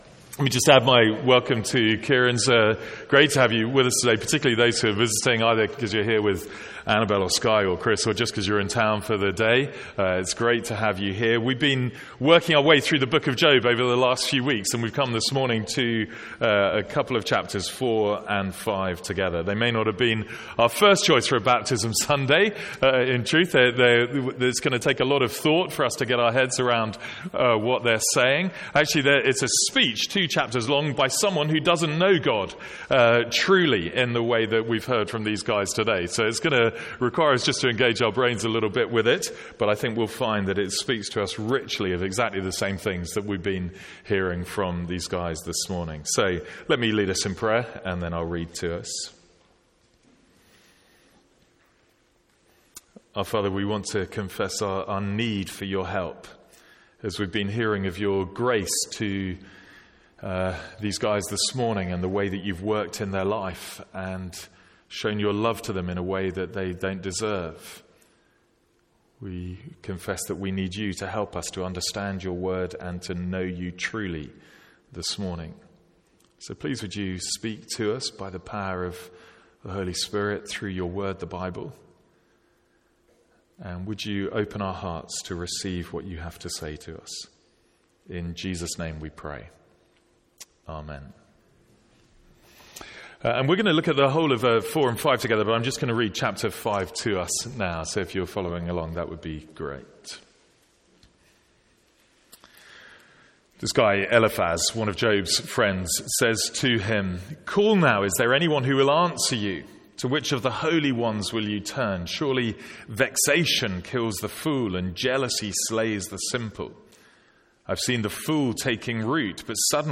Sermons | St Andrews Free Church
From our morning series in Job.